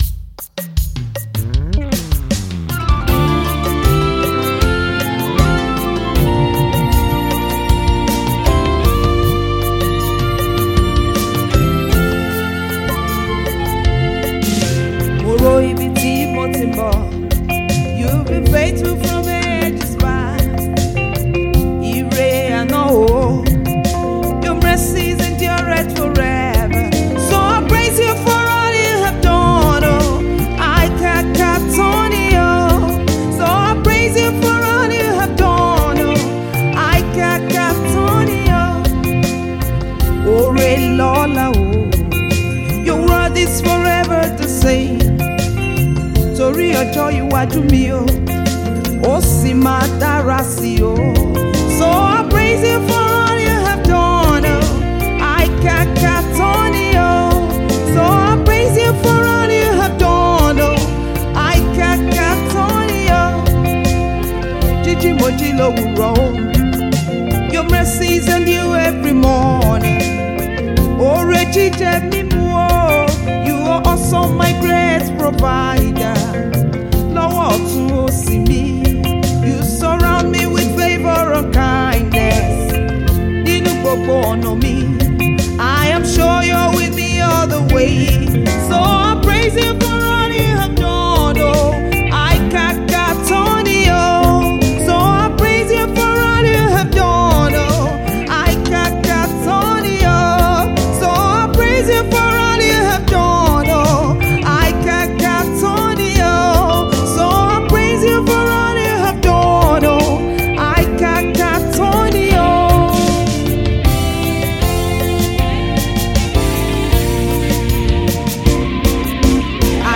a vibrant worship leader